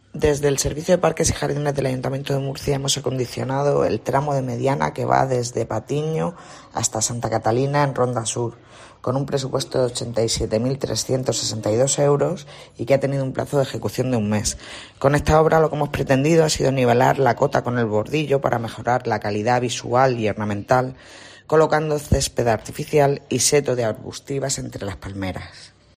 Ainhoa Sánchez, concejala de Pedanías y Barrios, Recursos Humanos y Desarrollo Urbano